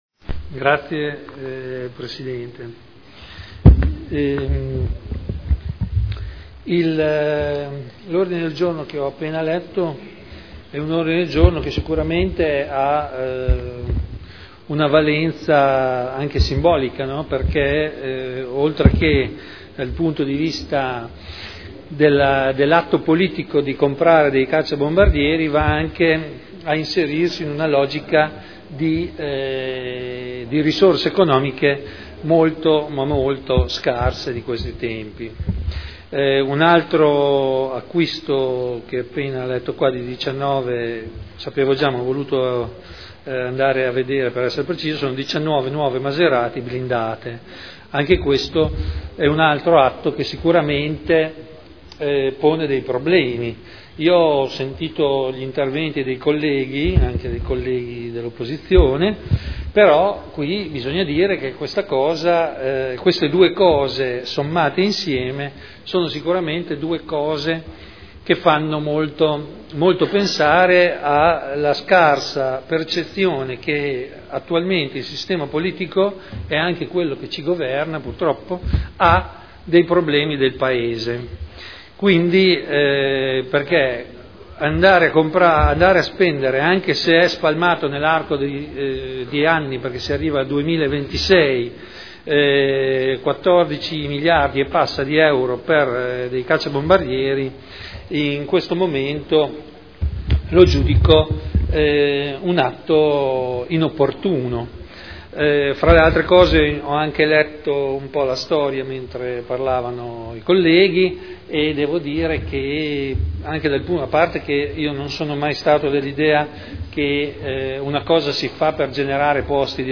Seduta del 7 novembre 2011